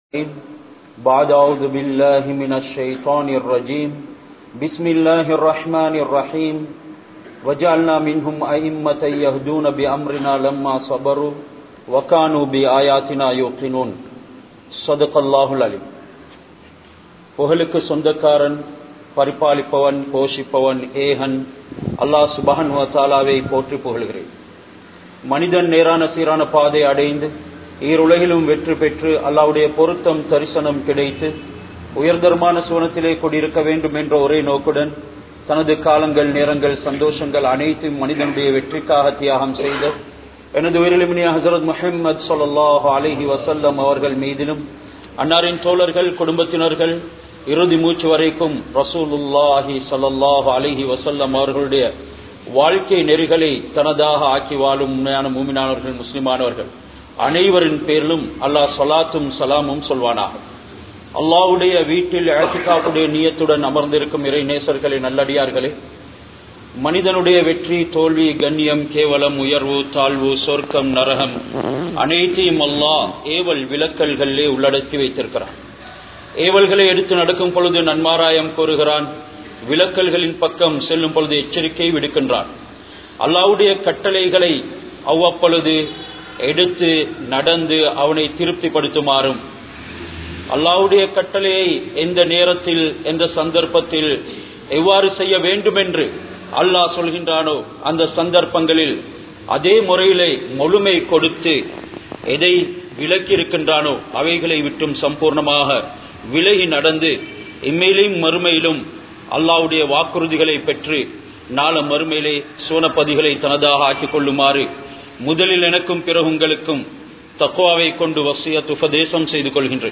Asma(Rali)Avarhalin Pasium Yoothanin Sappaadum (அஸ்மா(ரலி)அவர்களின் பசியும் யூதனின் சாப்பாடும்) | Audio Bayans | All Ceylon Muslim Youth Community | Addalaichenai
Kandy, Dhehiyanga, Muhiyadeen Jumua Masjidh